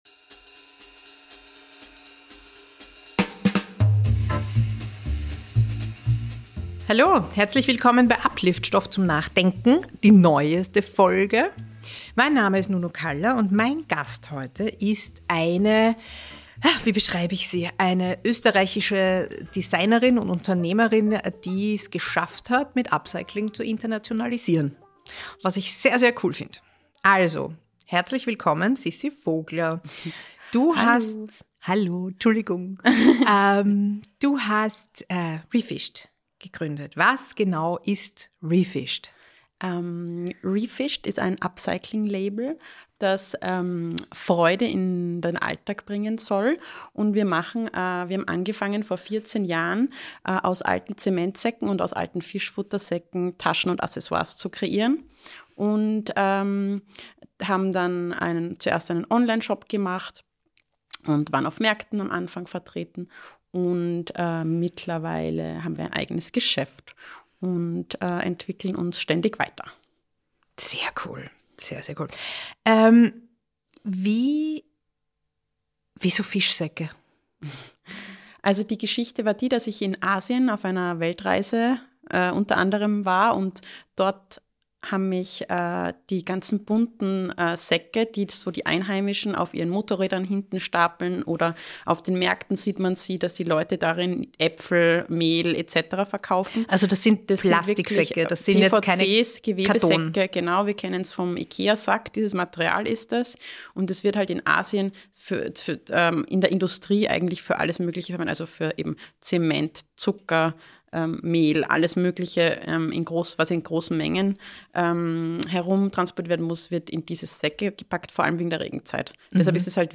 Ein spannendes Gespräch darüber, wie man es als kleines heimisches Label aufs internationale Parkett schaffen kann.